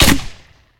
hkShoot.ogg